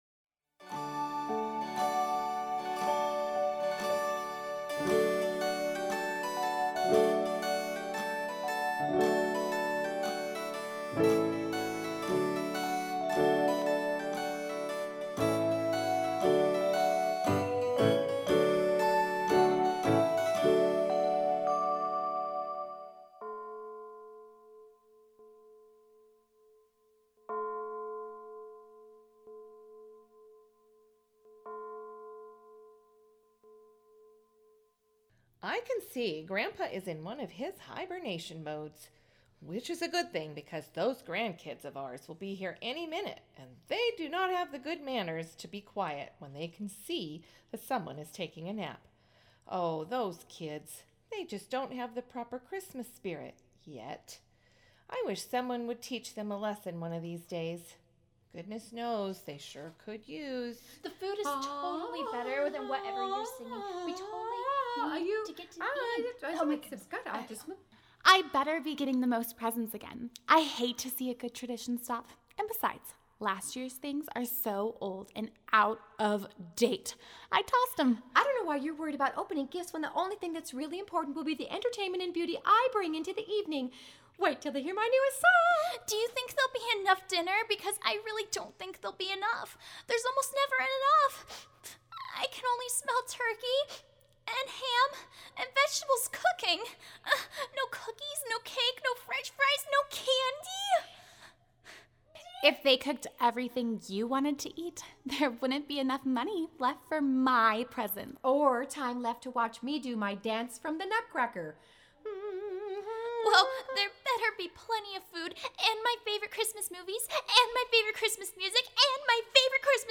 Sneak Preview –  Listen to these samples of the Audio Performances you will get with the Helpful Production Bundle for Just My Imagination.